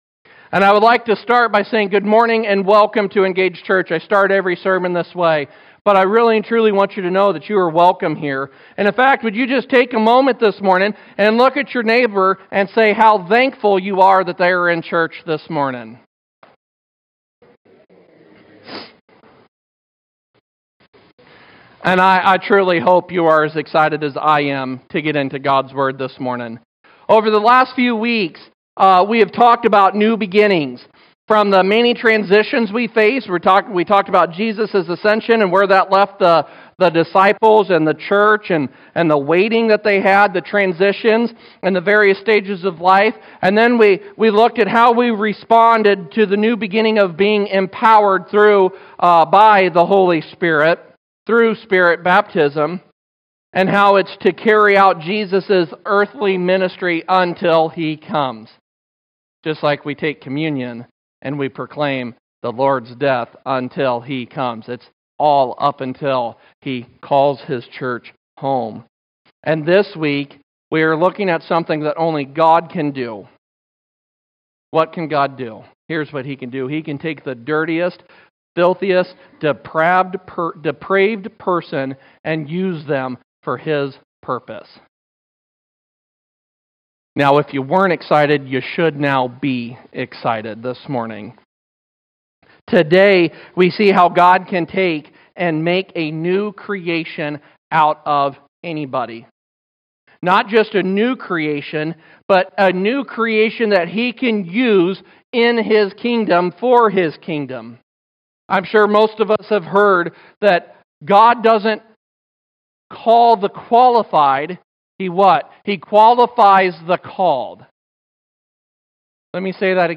Sermons | Engage Church